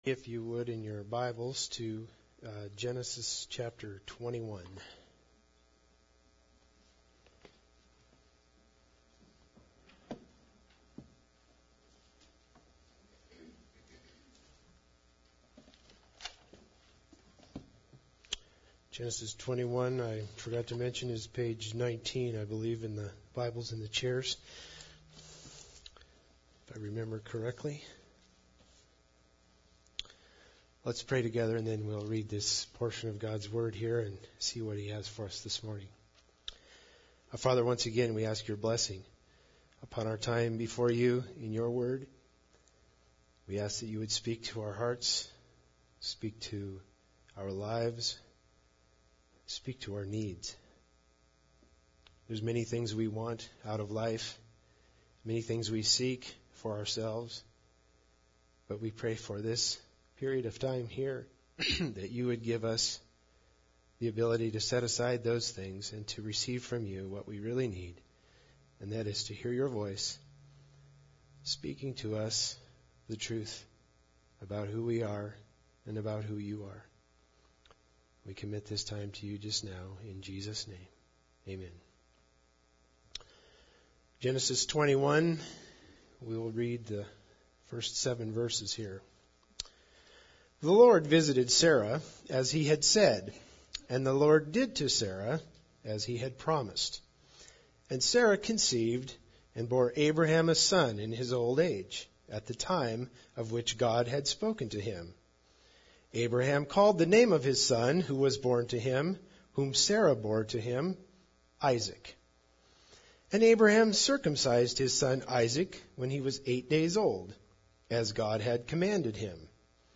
Genesis 21:1-7 Service Type: Sunday Service Bible Text